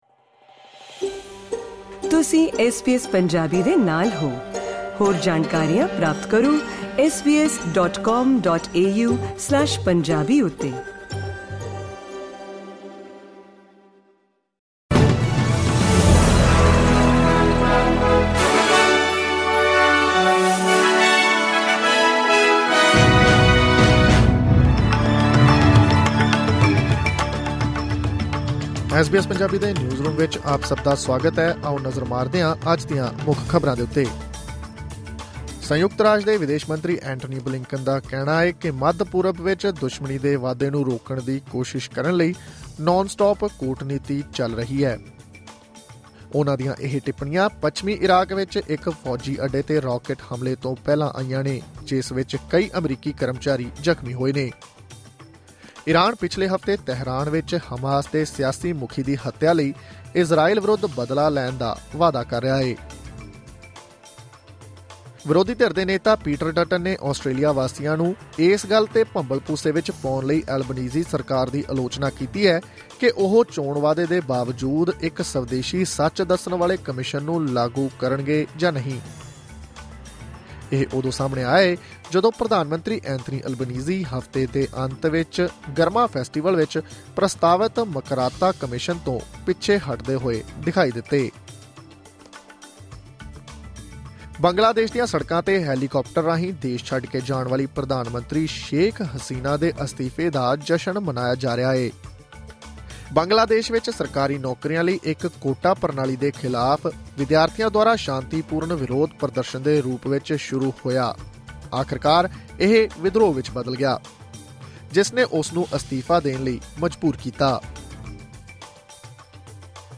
ਐਸ ਬੀ ਐਸ ਪੰਜਾਬੀ ਤੋਂ ਆਸਟ੍ਰੇਲੀਆ ਦੀਆਂ ਮੁੱਖ ਖ਼ਬਰਾਂ: 6 ਅਗਸਤ 2024